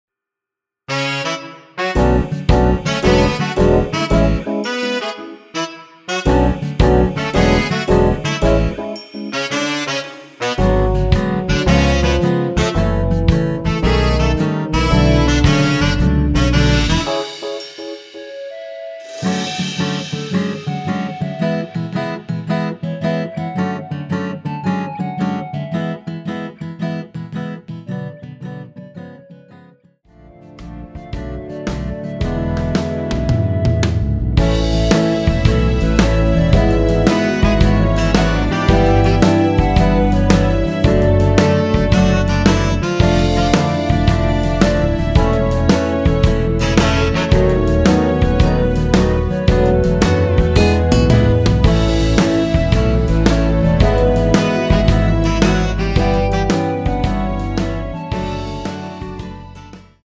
원키에서(-5)내린 남성분이 부르실수 있는 키의 멜로디 포함된 MR입니다.(미리듣기 확인)
앞부분30초, 뒷부분30초씩 편집해서 올려 드리고 있습니다.
중간에 음이 끈어지고 다시 나오는 이유는